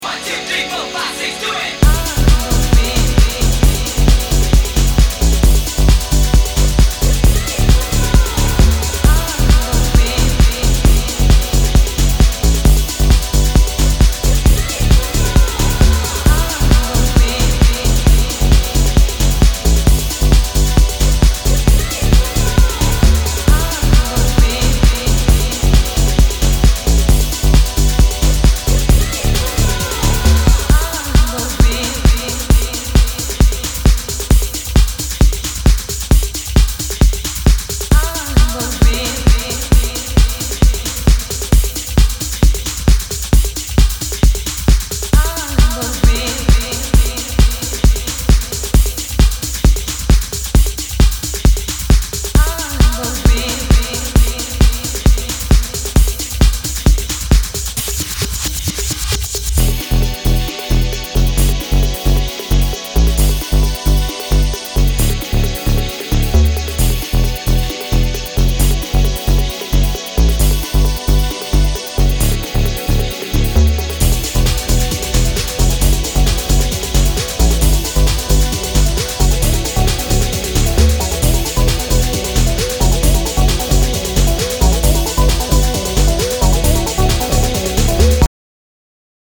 ユーフォリックなコードとヴォイス・サンプルがモメンタムに響く